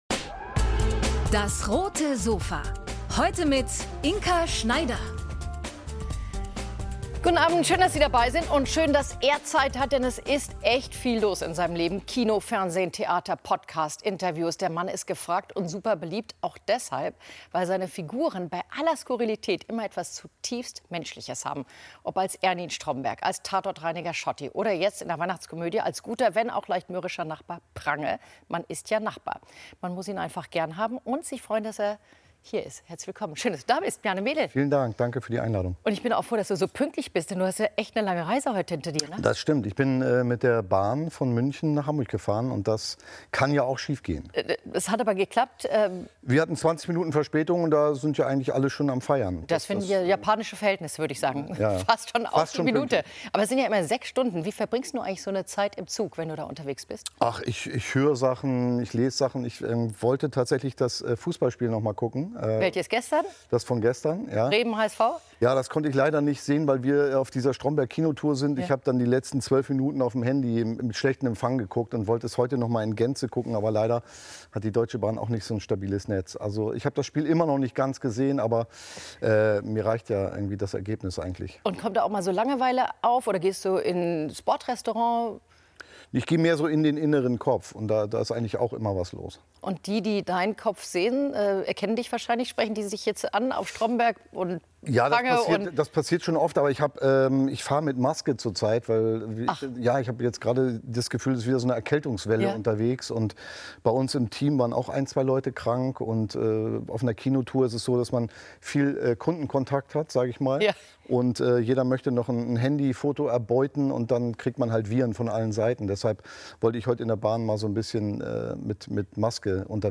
Ihm liegen schrullige Figuren: Schauspieler Bjarne Mädel ~ DAS! - täglich ein Interview Podcast